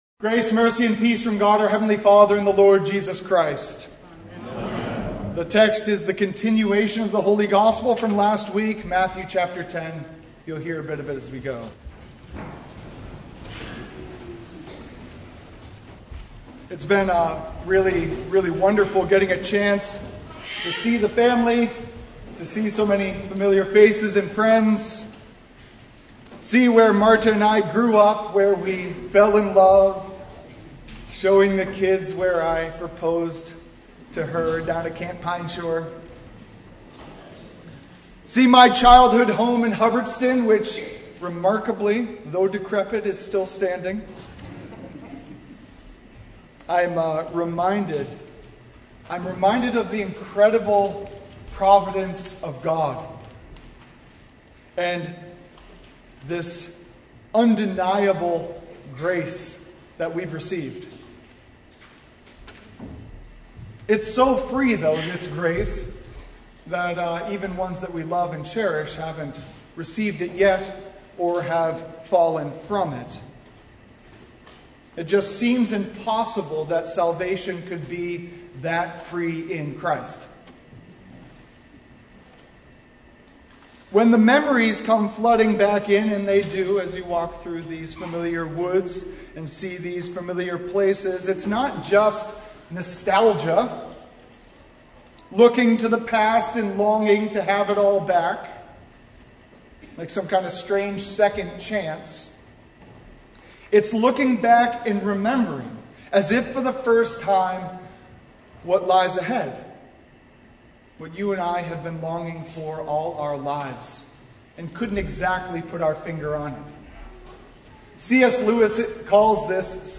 Sermon from First Sunday in Apostles Tide (2023)
Passage: Matthew 10:34-42 Service Type: Sunday